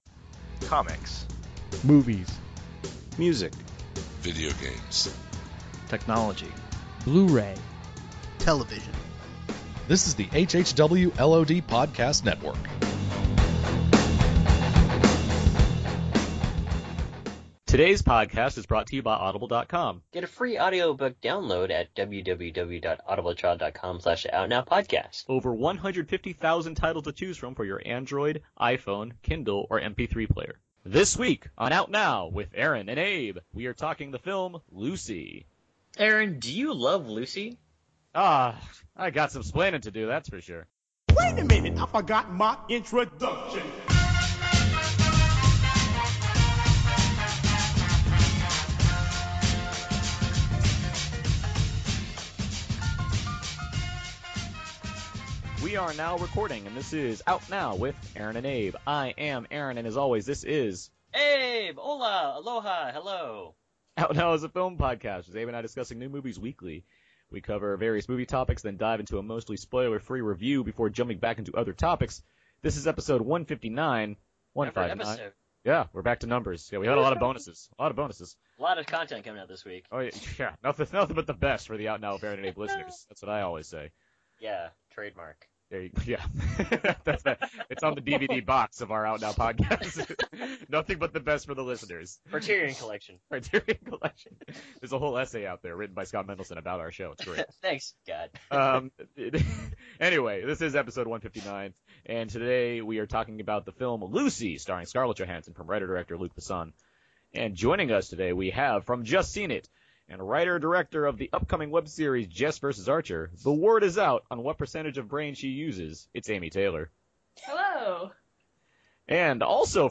It’s a fun, upbeat episode this week, especially given all the Comic Con exhaustion going on.